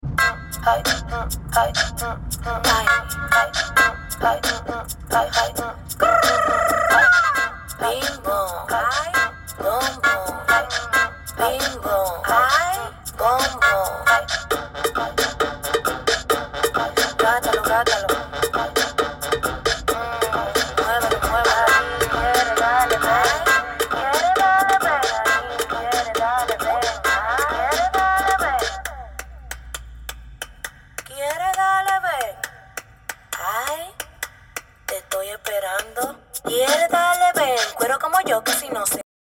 Time to work on the bass